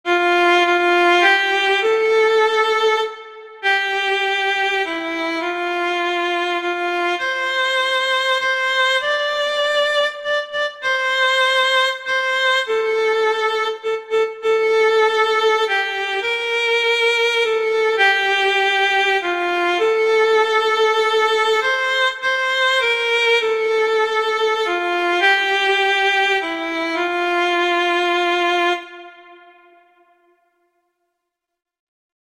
violin solo